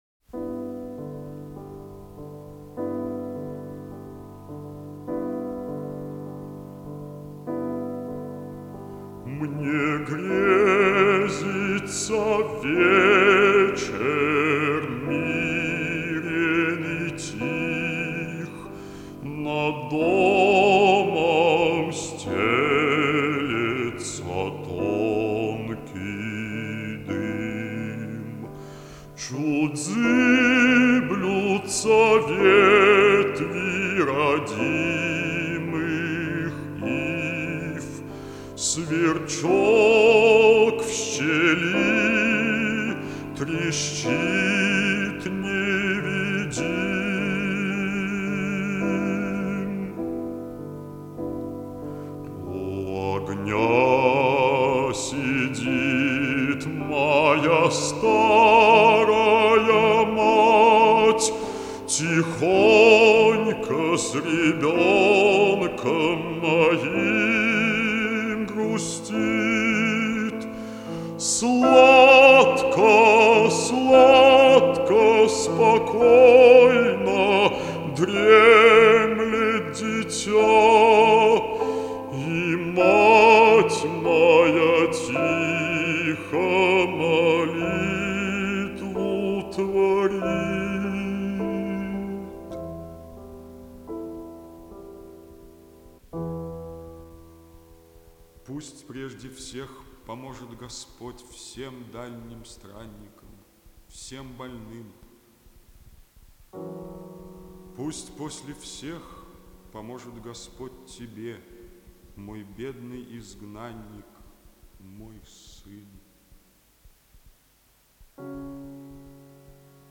Mūzikas ieraksts